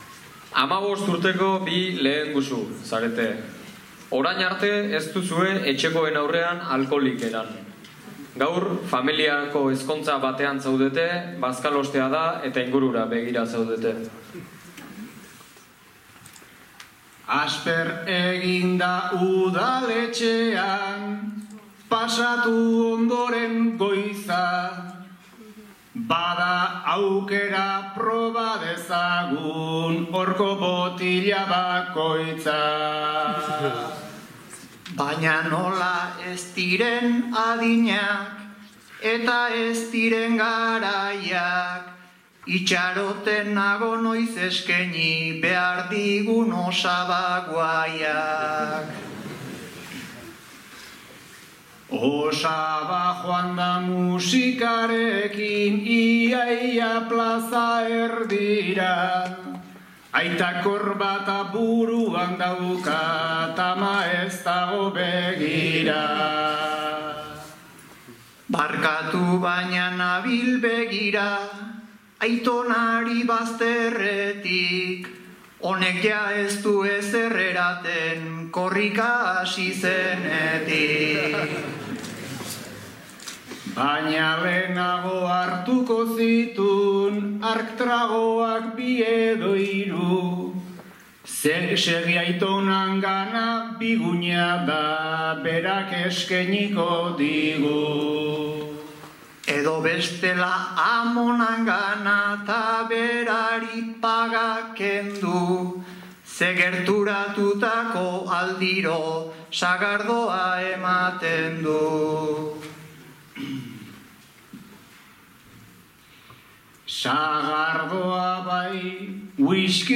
Gipuzkoako Bertsolari Txapelketa. 2. Final zortzirena
Lasarte-Oria (Gipuzkoa)2024-09-15